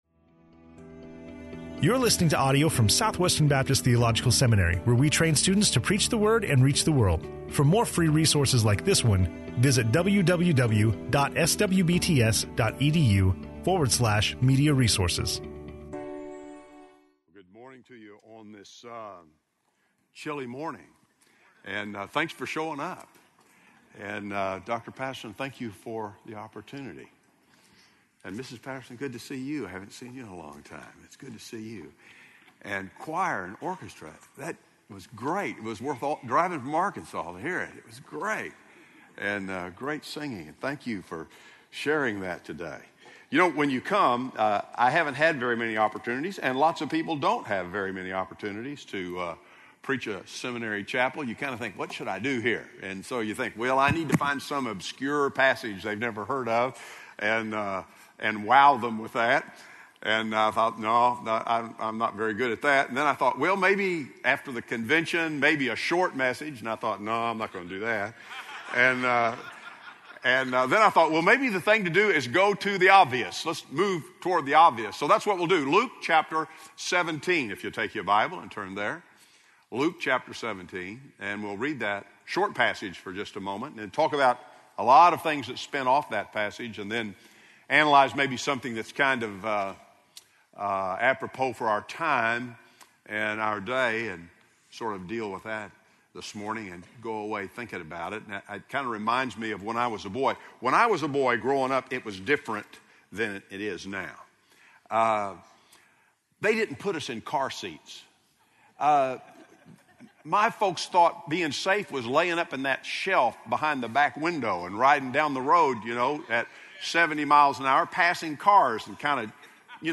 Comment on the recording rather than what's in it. speaking on Luke 17:20-21 in SWBTS Chapel